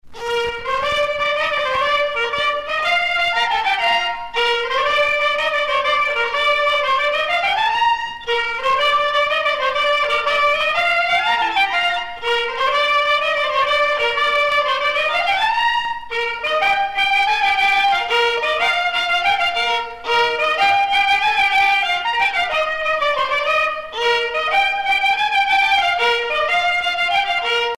Usage d'après l'analyste gestuel : danse
Genre brève
Pièce musicale éditée